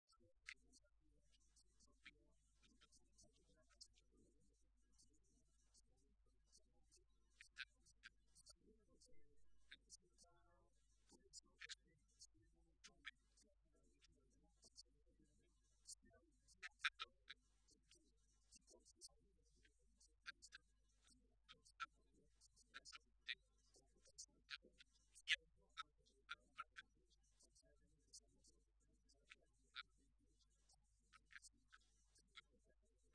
José Molina, viceportavoz del Grupo Parlamentario Socialista
Cortes de audio de la rueda de prensa